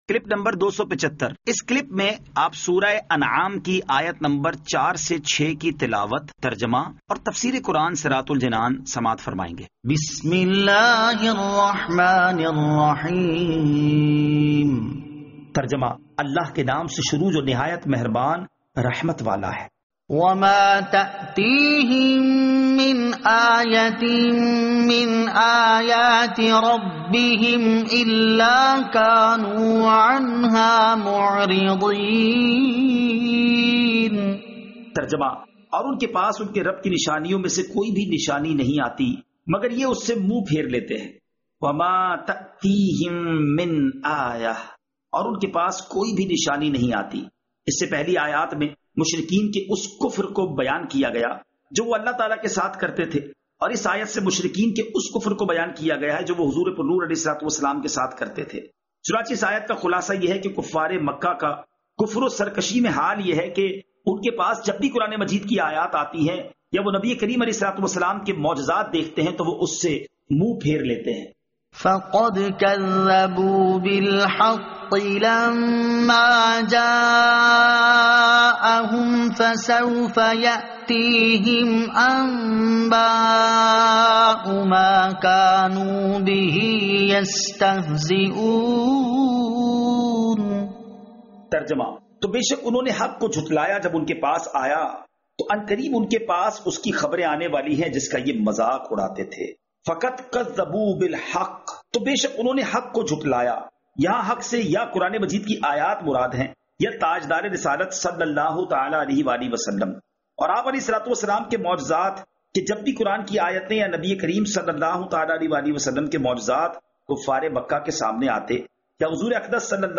Surah Al-Anaam Ayat 04 To 06 Tilawat , Tarjama , Tafseer